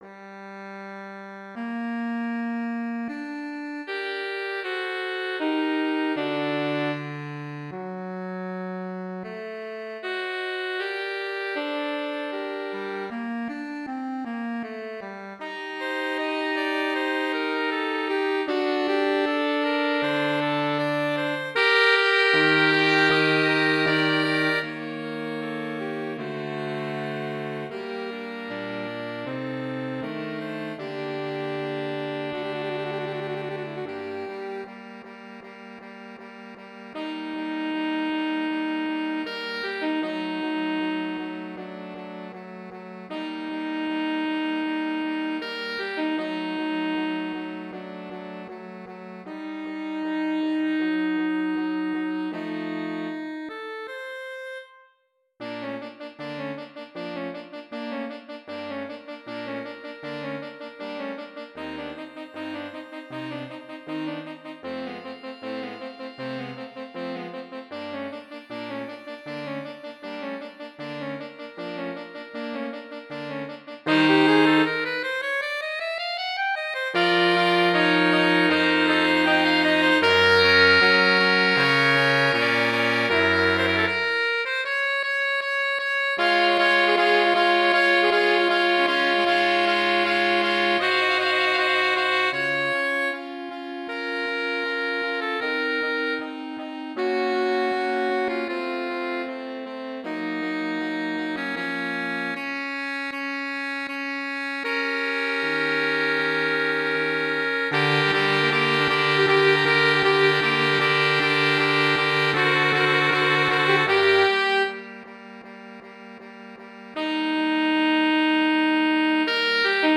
Andante.